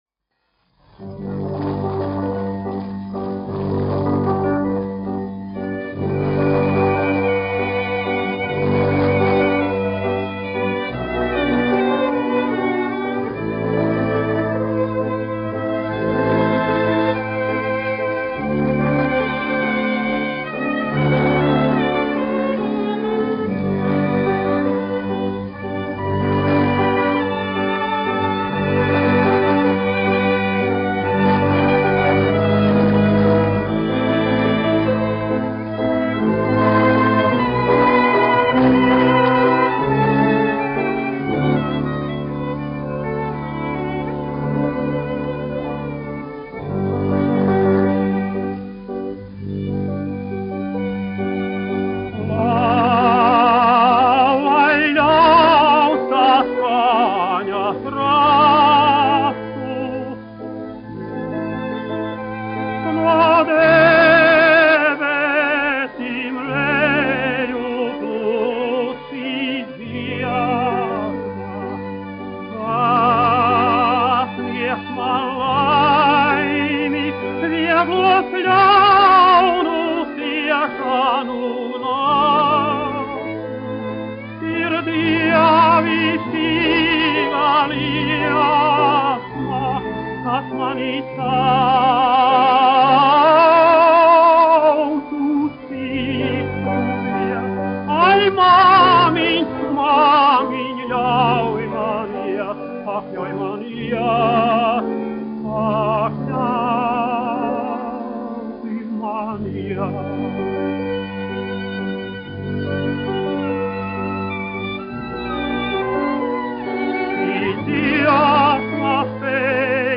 1 skpl. : analogs, 78 apgr/min, mono ; 25 cm
Dziesmas (augsta balss) ar instrumentālu ansambli
Latvijas vēsturiskie šellaka skaņuplašu ieraksti (Kolekcija)